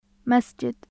日本語の「ダ」と同じ/d/の音です。
/d/ 有声・歯茎・破裂音/d/ د /daːl/ d （アルファベット） دار /daːr/ 家 جدار /jidaːr/ 壁 دين /diːn/ 宗教 جديد /jadiːd/ 新しい بدون /biduːn/ ～なし，～抜き جدد /judud/ 新しい مدرسة /madrasa/ 学校 مسجد /masjid/ 礼拝所，モスク